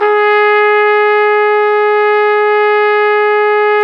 Index of /90_sSampleCDs/Roland L-CD702/VOL-2/BRS_Flugelhorn/BRS_Flugelhorn 2